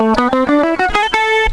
La gamme mineure mélodique est une gamme mineure harmonique dont on a haussé le sixième degré d'un demi-ton.
Gamme Mineure Mélodique de La (cliquez pour écouter)